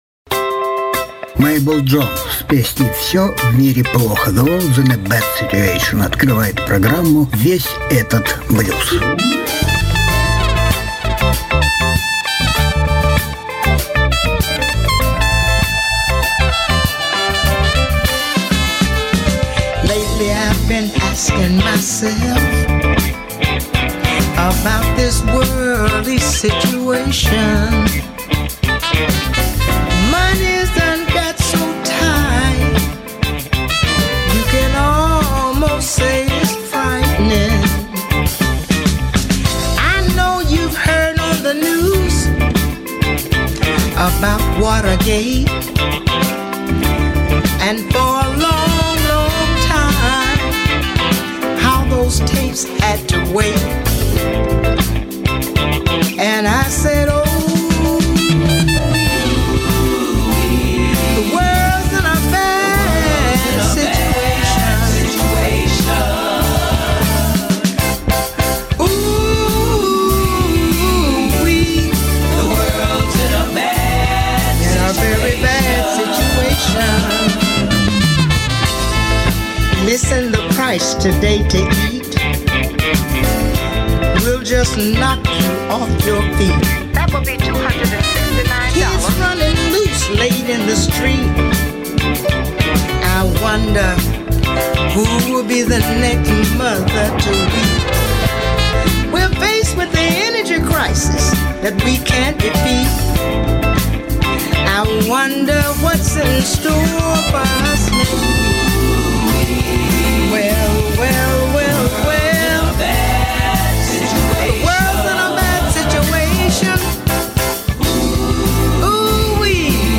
Жанр: Блюз